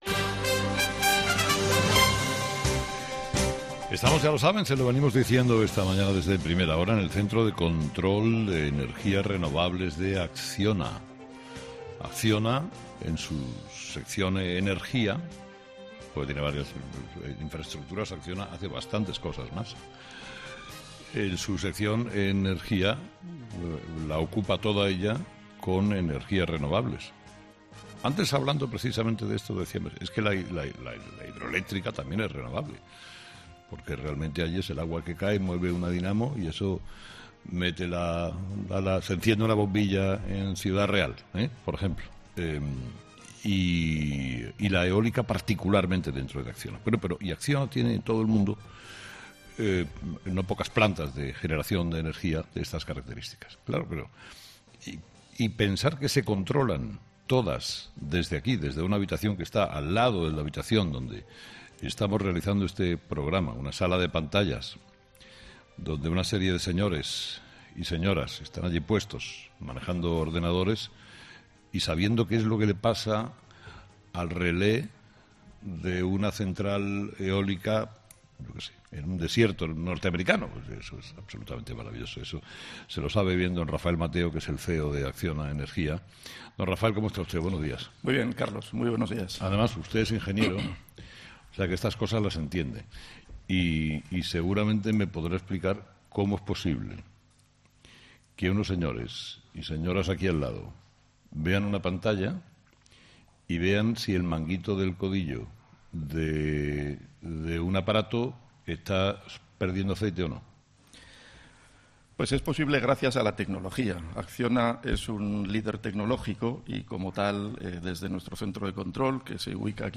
AUDIO: Este martes 'Herrera en COPE' se emite desde la sede de Acciona en Navarra.